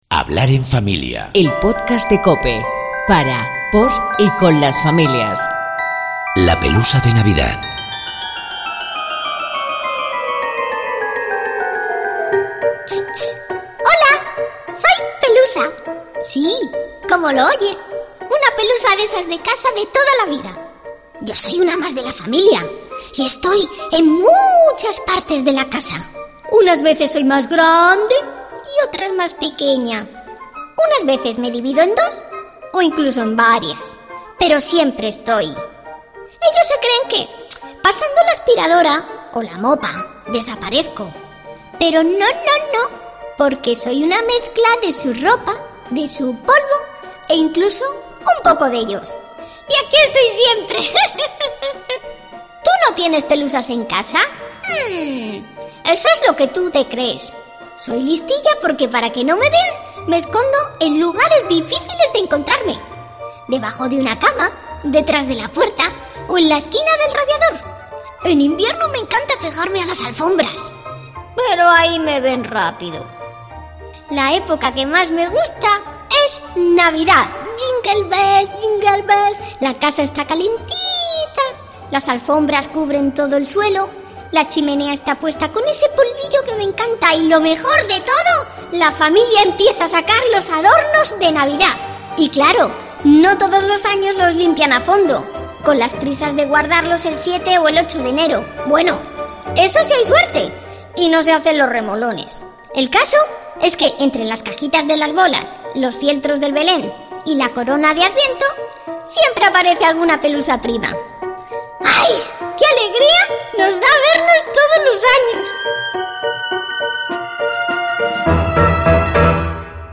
Un cuentacuentos para que hables del sentido de la Navidad con tus hijos y lo compartas como felicitación. Herrera y Expósito, entre las voces invitadas